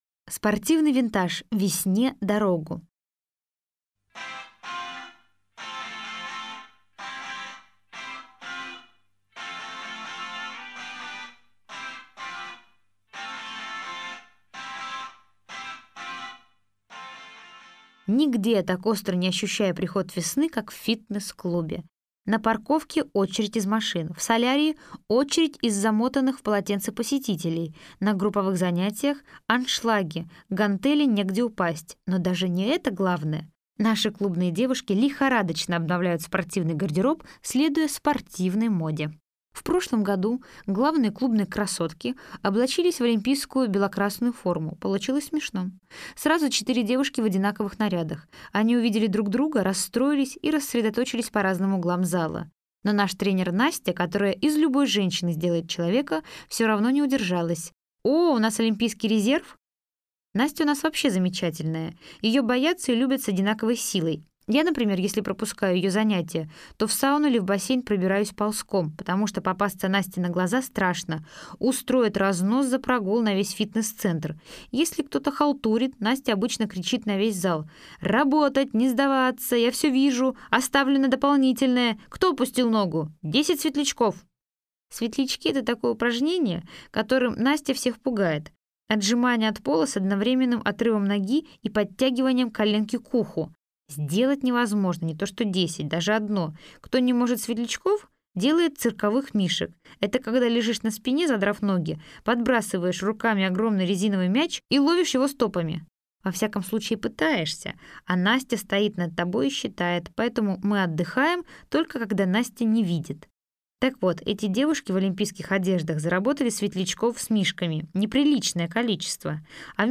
Аудиокнига Вся la vie | Библиотека аудиокниг